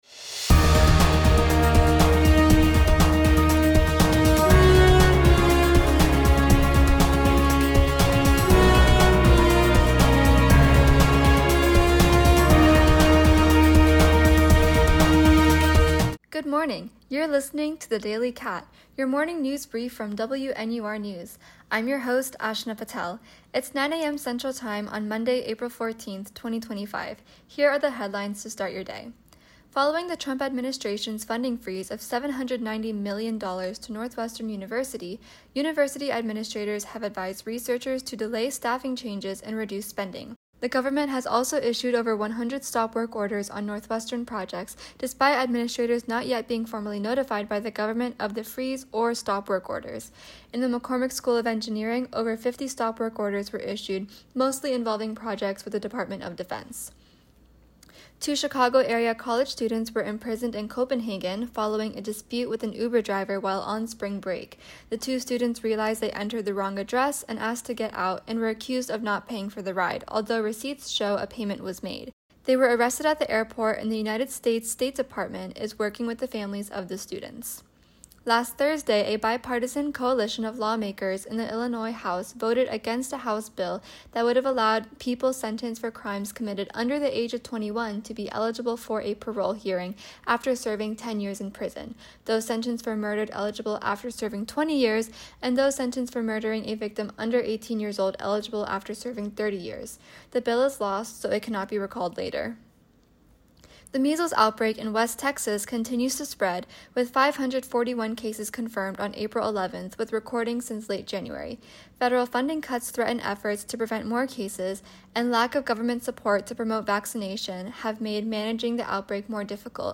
Aril 14, 2025 Northwestern University officials recommend researchers to delay staffing changes amongst federal funding cuts and stop work orders, Chicago area college students imprisoned in Denmark, bill voted against in Illinois House, measles outbreak continues in Texas, and The United States and Iran have began indirect nuclear negotiations. WNUR News broadcasts live at 6 pm CST on Mondays, Wednesdays, and Fridays on WNUR 89.3 FM.